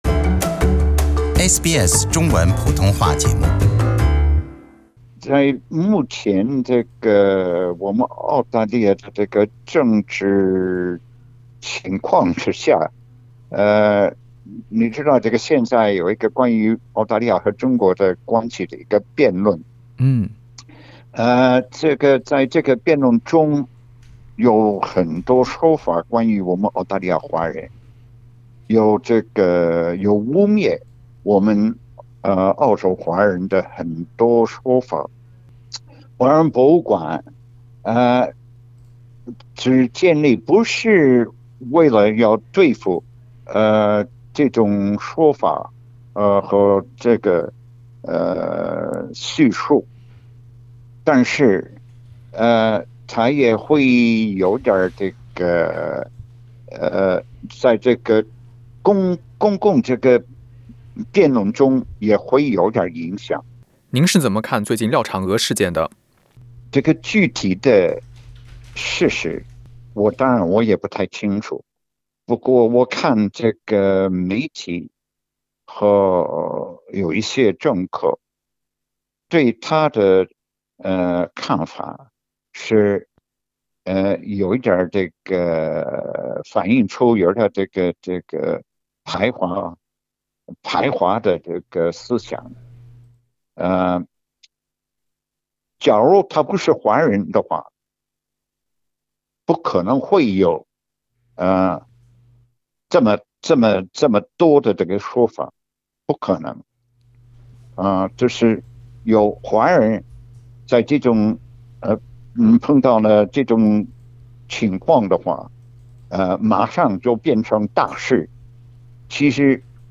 近日，就澳洲华人博物馆项目在悉尼启动一事，费思棻博士接受了SBS中文普通话的采访。